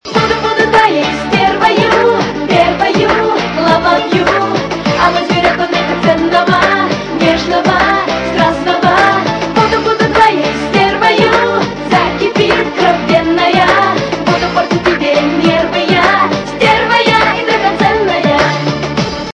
русская эстрада
При заказе вы получаете реалтон без искажений.